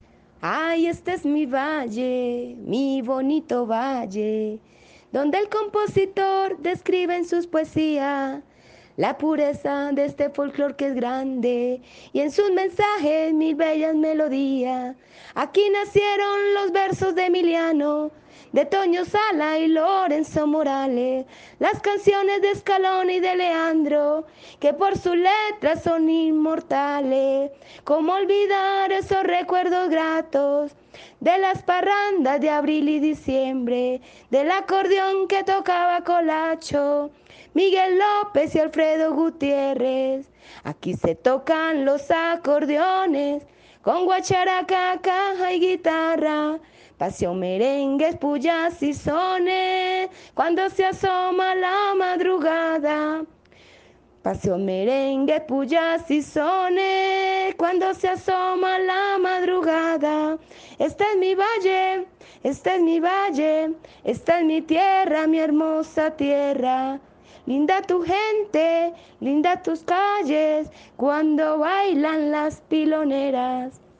un paseo dedicado a Valledupar, titulado “Mi lindo valle”
parte de la canción cantada a Capela